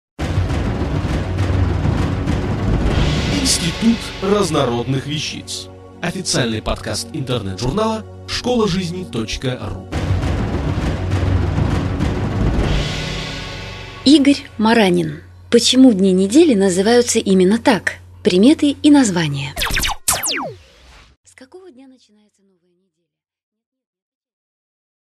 Аудиокнига Почему дни недели называются именно так? Приметы и названия | Библиотека аудиокниг